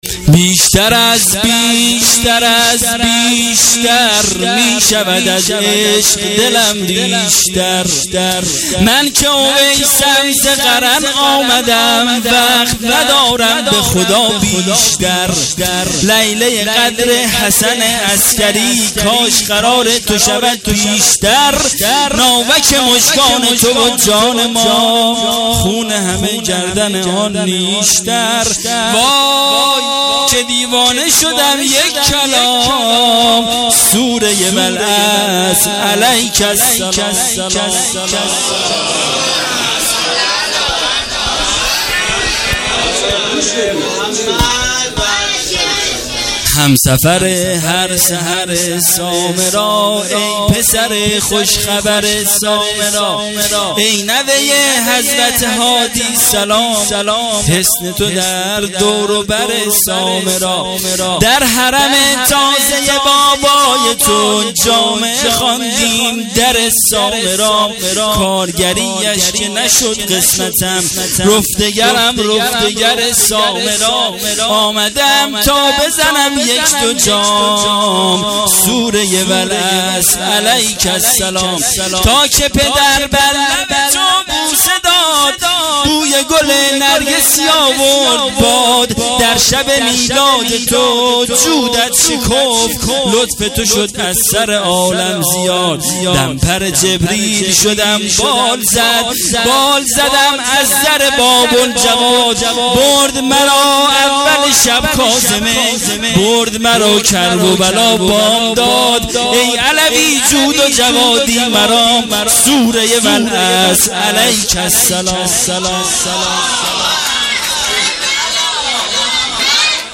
جشن میلاد حضرت علی اکبر(ع) و امام زمان (عج)